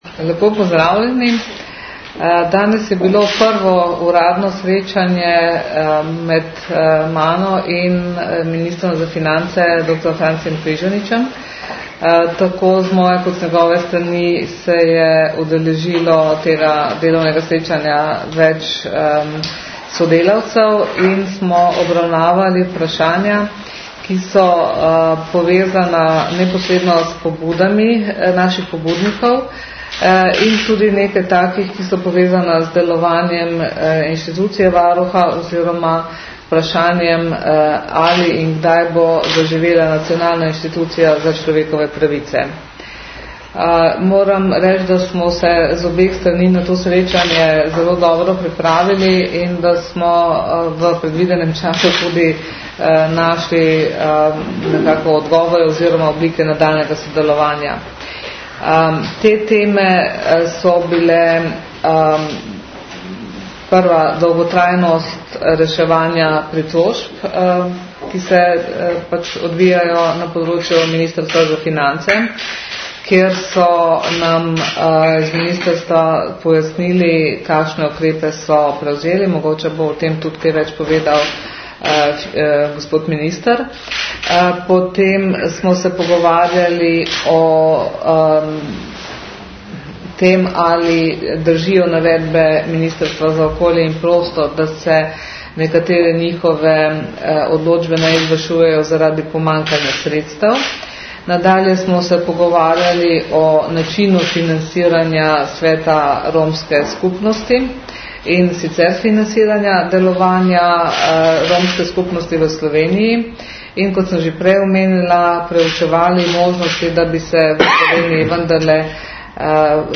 Zvočni posnetek izjave (MP3)
Po zaključku pogovora sta varuhinja in minister podala izjavo za javnost o obravnavanih temah.